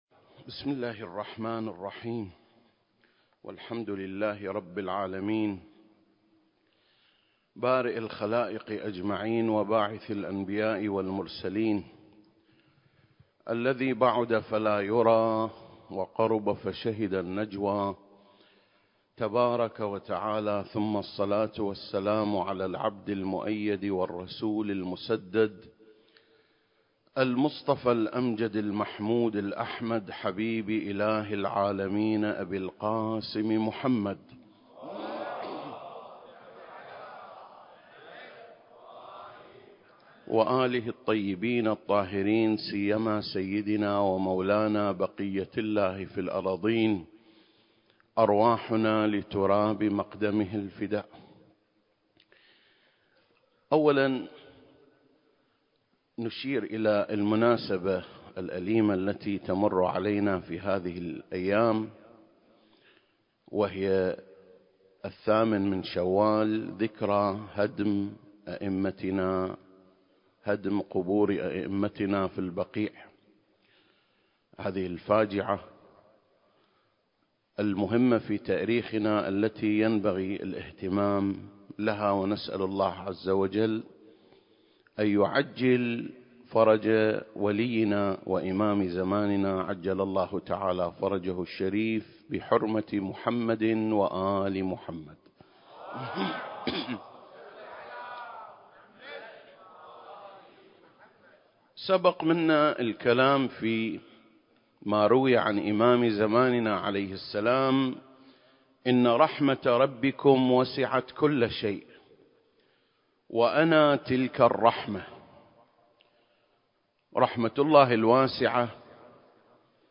عنوان الخطبة: المهدي (عجّل الله فرجه) رحمة الله الواسعة (2) المكان: مسجد مقامس/ الكويت التاريخ: 2024